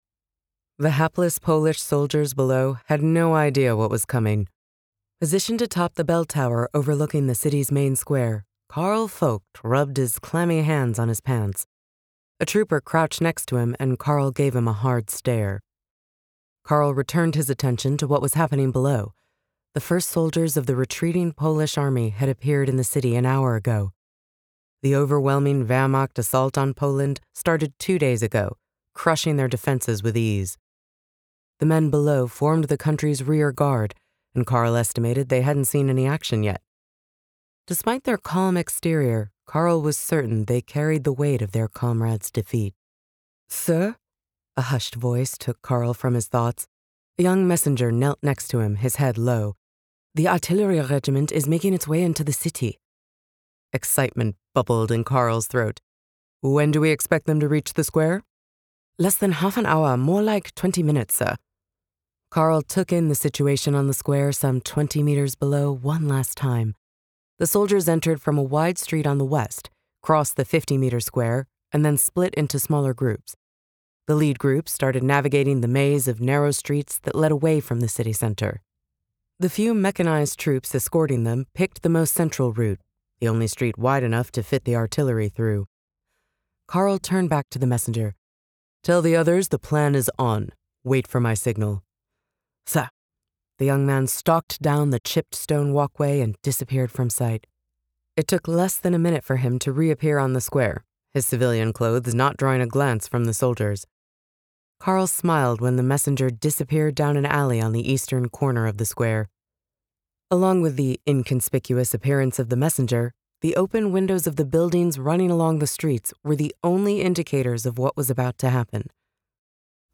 Phoenix Rising audiobook - Michael Reit - Buy direct from author!